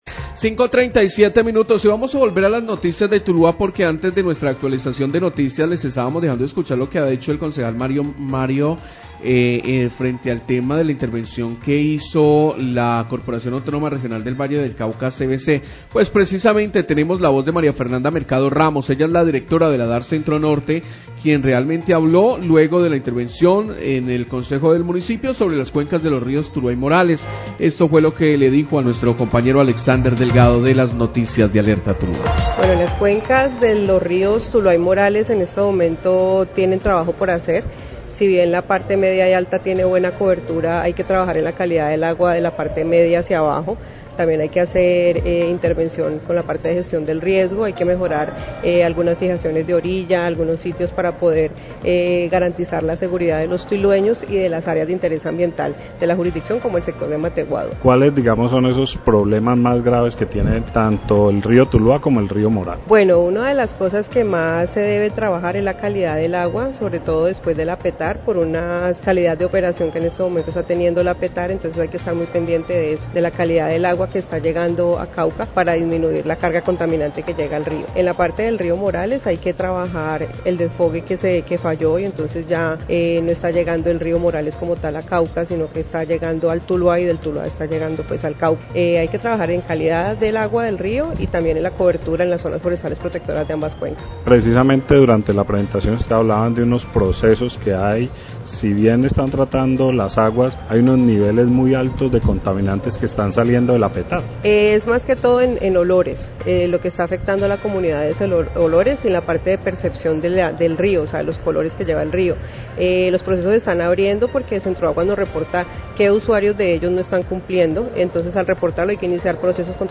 Radio
La directora de la DAR Centro Norte de la CVC, María Fernanda Mercado, habla de su intervención ante el Concejo de Tuluá sobre la intervención en la cuenca del Río Tuluá y el Río Morales. Describe los problemas de estas cuencas.